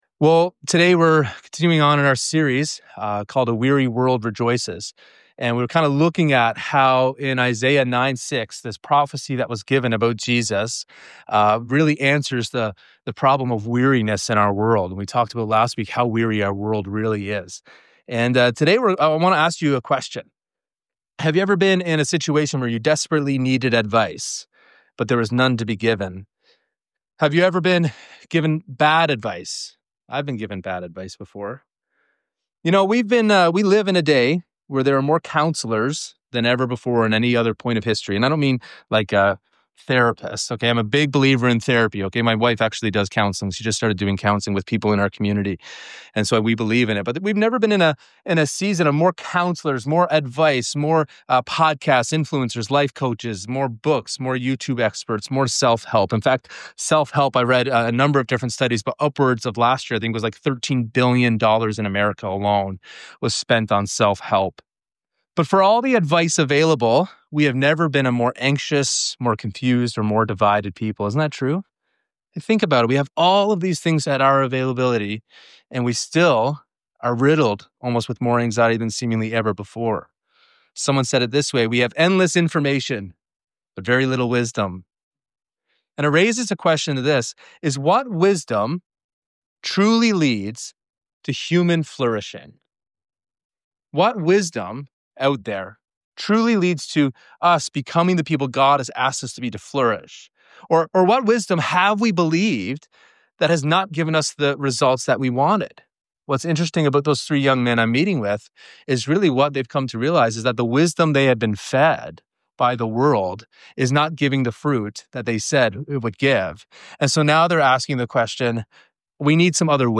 In this Advent message on Isaiah 9:6, we explore Jesus as the Wonderful Counsellor, the embodiment of God’s wisdom, whose way of life is radically different from the wisdom of the world. From the upside-down teachings of Jesus to the promise of the Holy Spirit as our ongoing guide, this sermon invites us to build our lives on the wisdom that truly leads to flourishing.